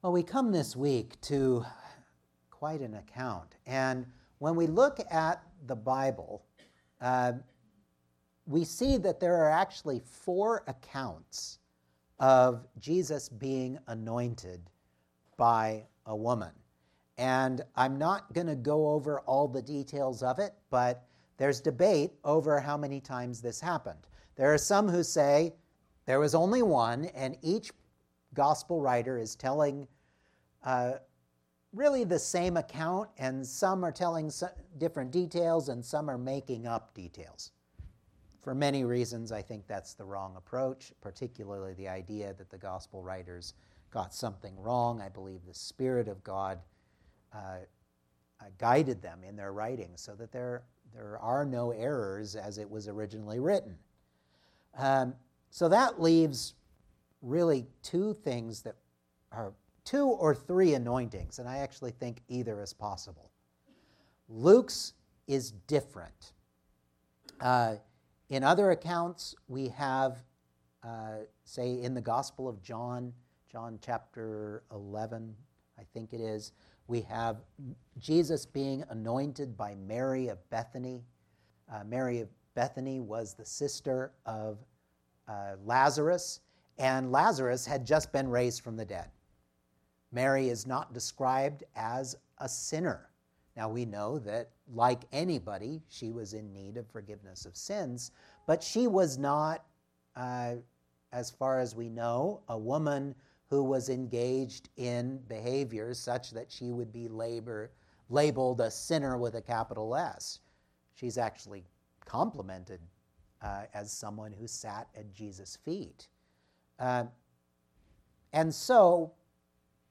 Passage: Luke 7:36-50 Service Type: Sunday Morning